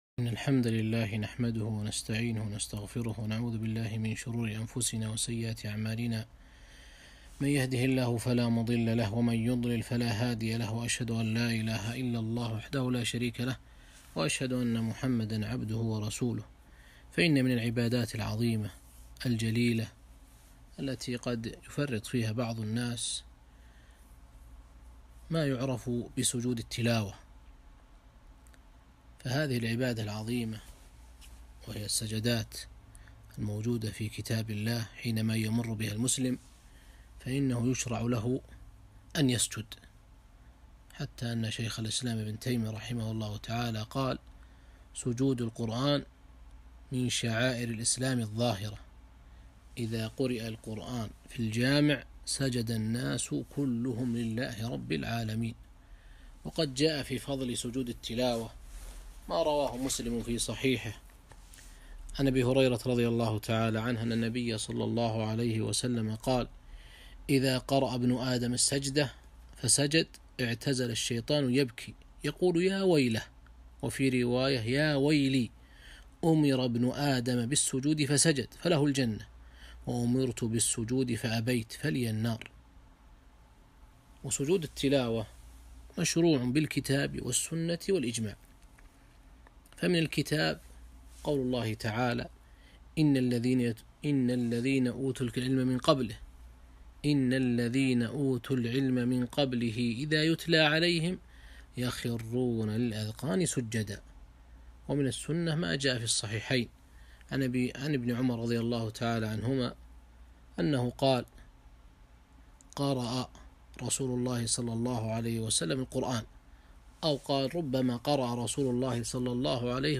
محاضرة - مسائل في سجود التلاوة